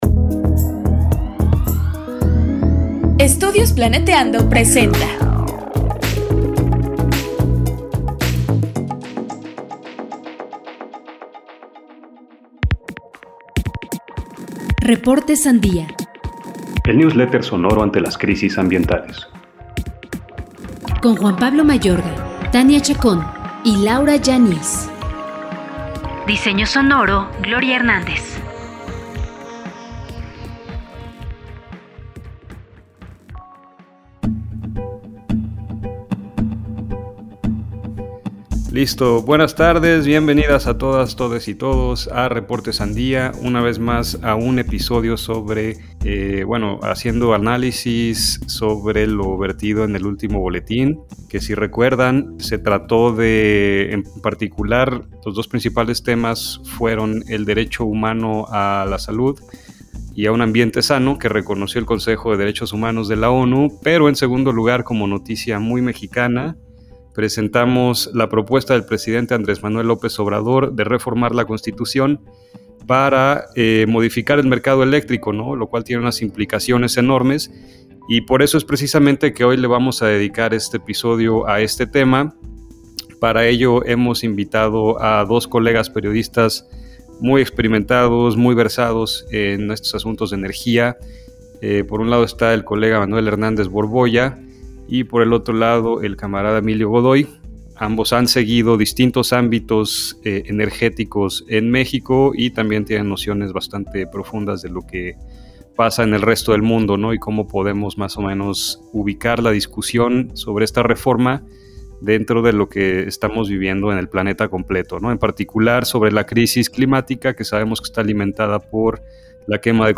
Entrevista: Análisis ambiental de la reforma energética en México